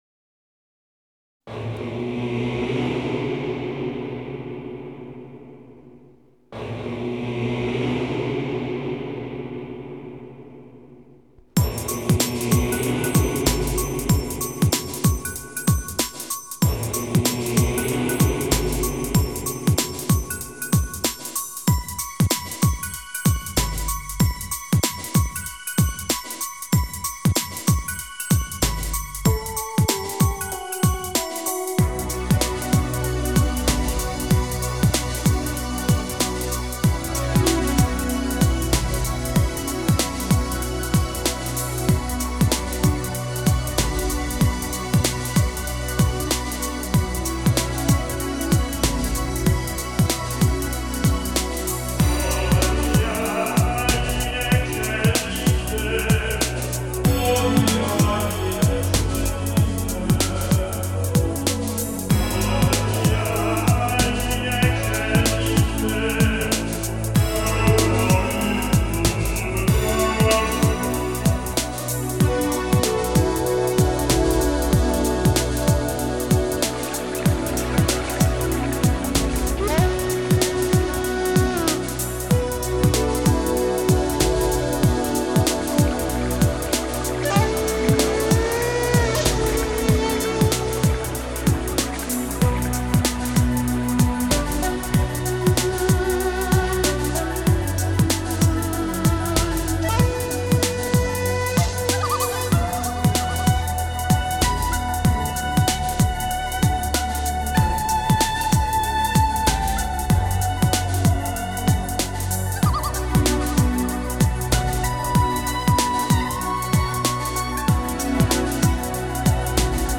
Genre: Enigmatic.